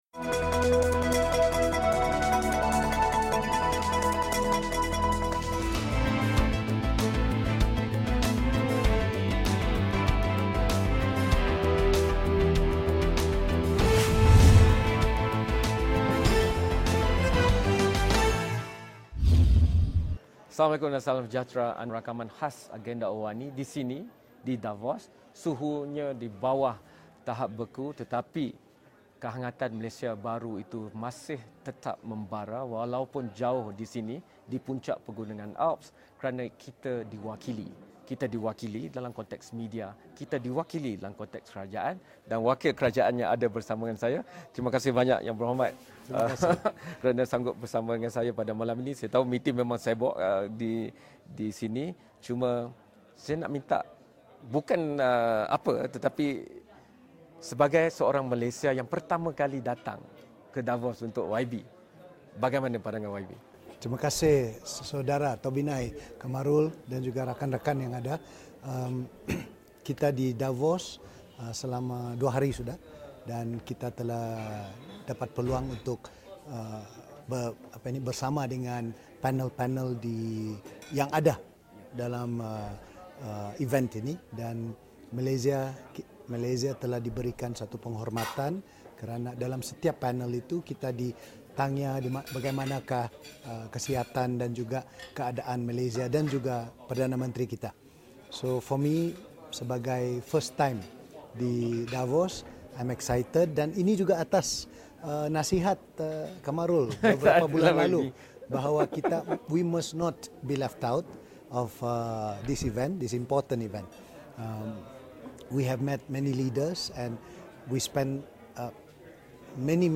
Wawancara